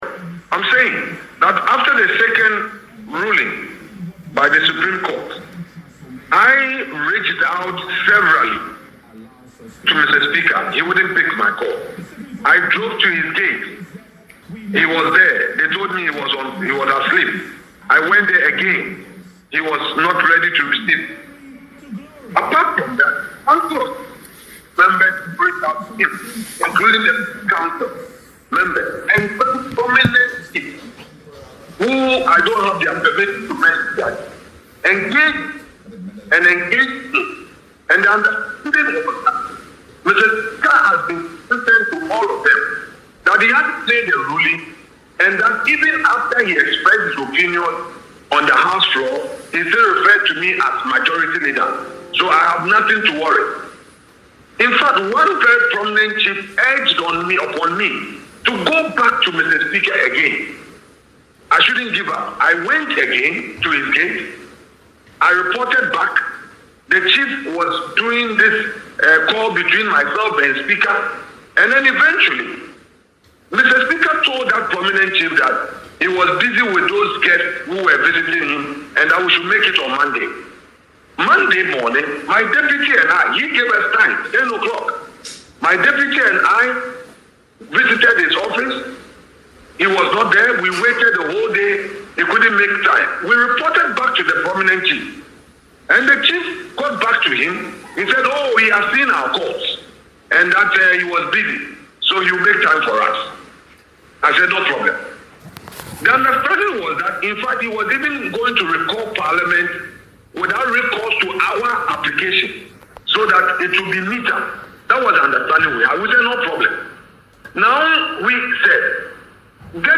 Afenyo-Markin laid the blame on Newsfile on Joy FM at the weekend.
Listen to Afenyo-Markin tell his own story in the attached audio.